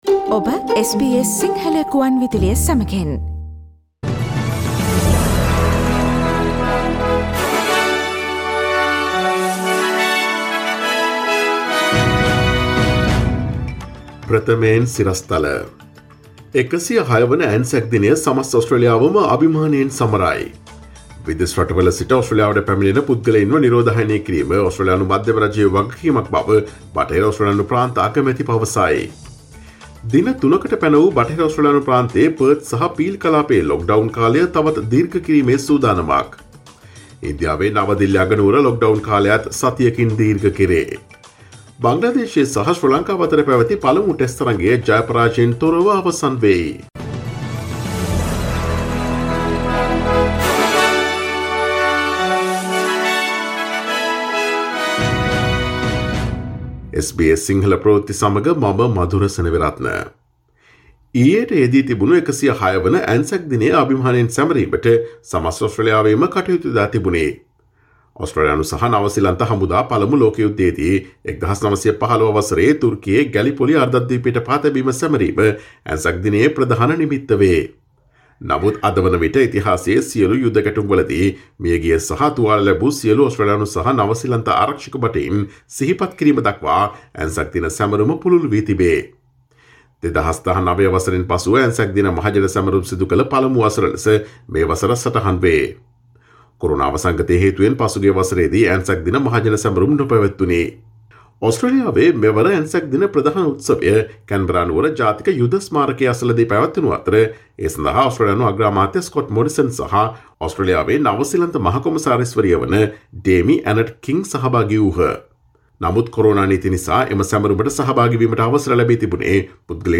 Here are the most prominent Australian, International, and Sports news highlights from SBS Sinhala radio daily news bulletin on Monday 26 April 2021.